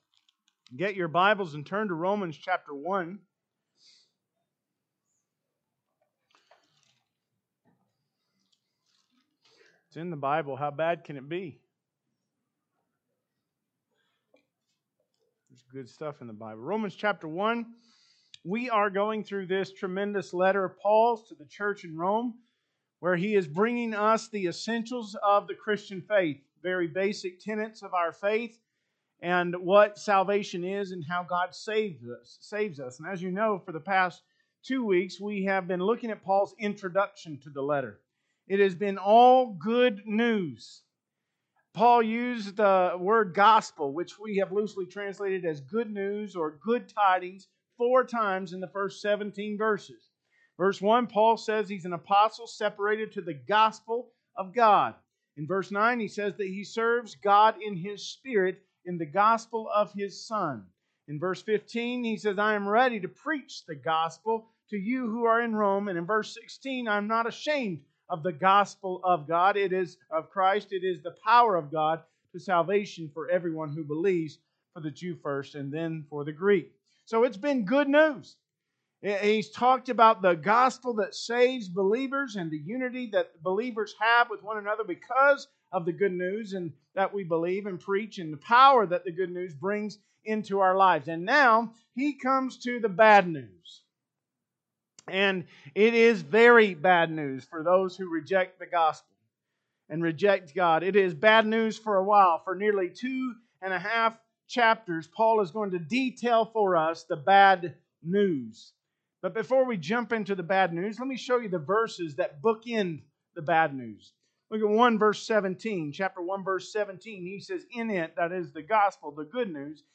Sermons | Smith Valley Baptist Church